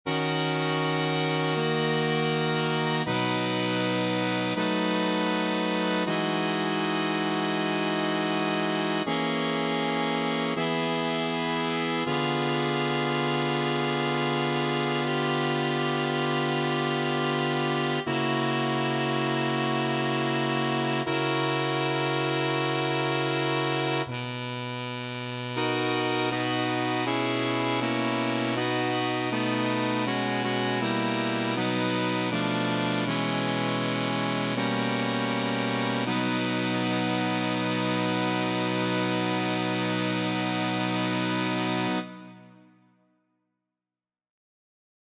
Key written in: B Major
How many parts: 4
Type: Barbershop
All Parts mix: